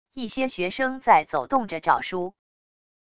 一些学生在走动着找书wav音频